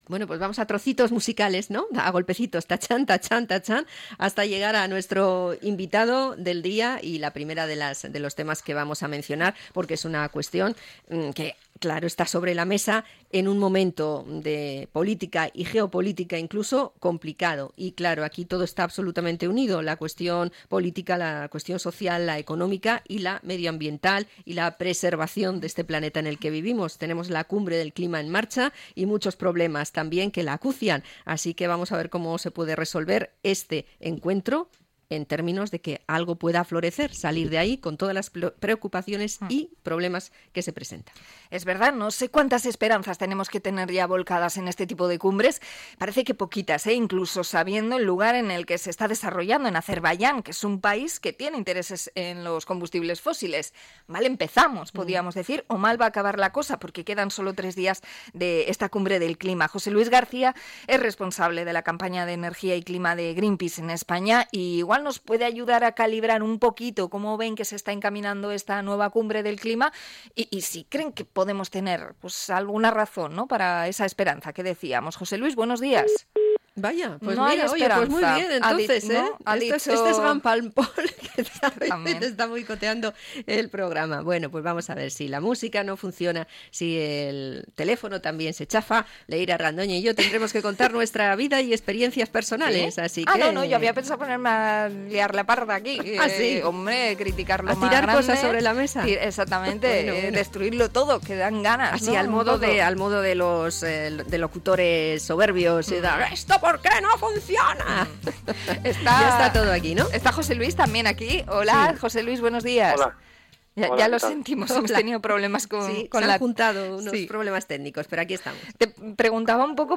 Entrevista a Greenpeace por el impuesto a las eléctricas y la COP